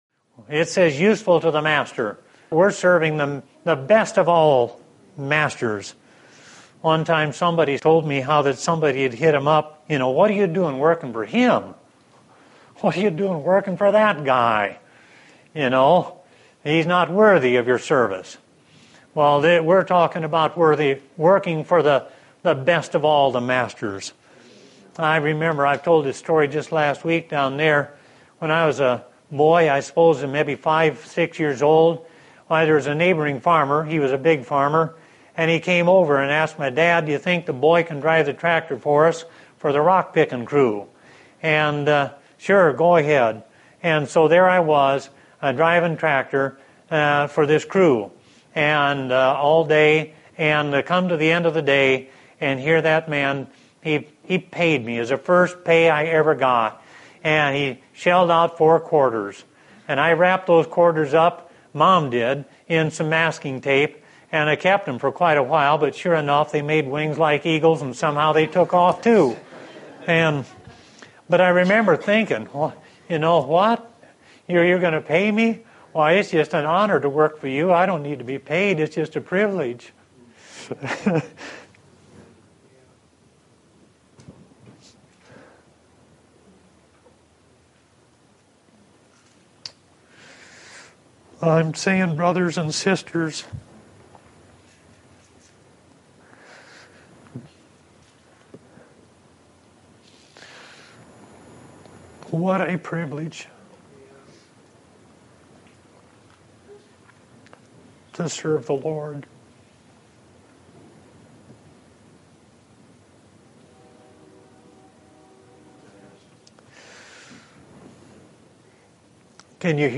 2017 Category: Excerpts Topic